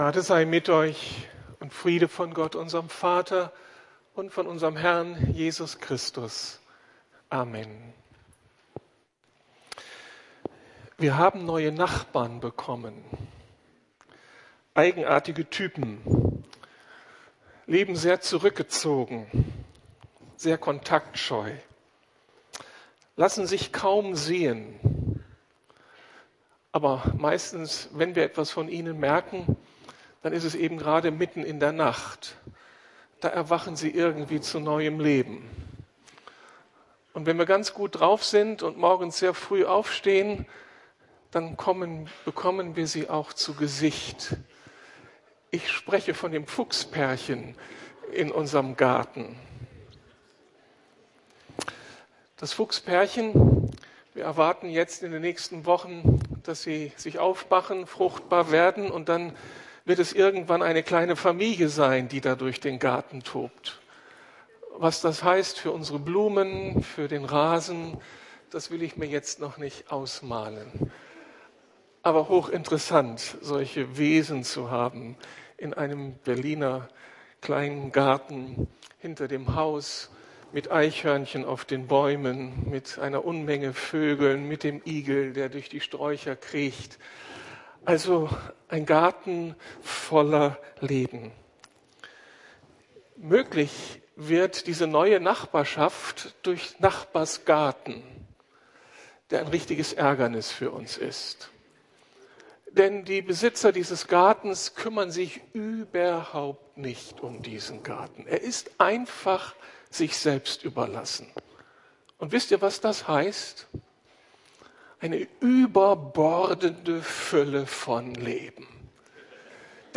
Seid fruchtbar und mehret euch - Reproduktion als Lebensprinzip I ~ Predigten der LUKAS GEMEINDE Podcast